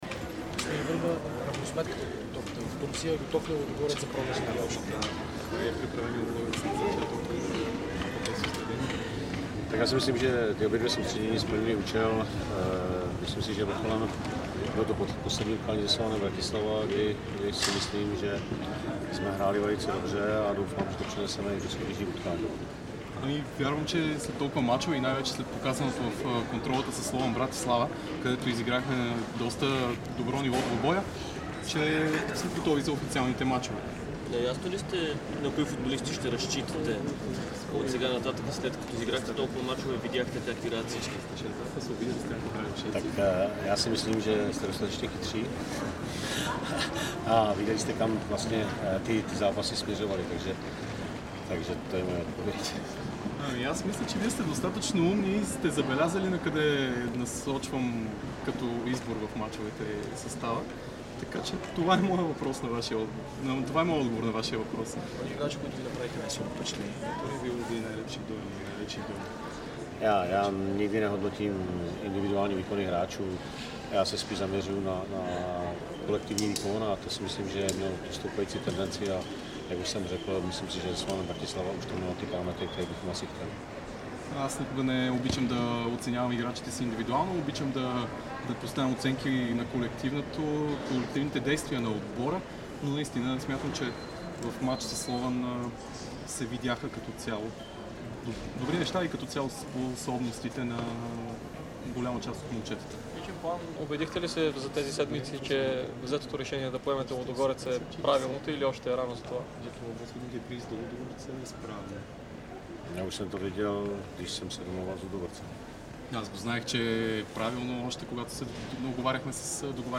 Старши треньорът на Лудогорец Павел Върба говори пред журналистите след завръщането на отбора от дългия лагер в Турция. Той заяви, че цел номер 1 пред тима е спечелването на шампионската титла, а успех над Интер ще е бонус. Върба призна, че иска ново попълнение на една позиция.